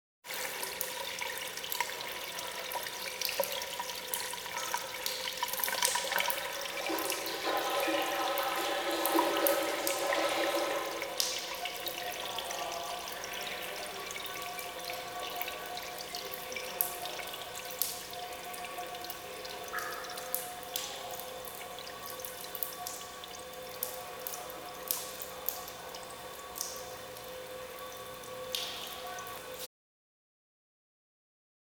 Shower Running Sound
household
Shower Running